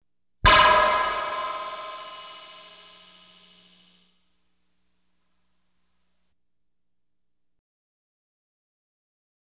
Light Beam Hit Sound Effect
Download a high-quality light beam hit sound effect.
light-beam-hit.wav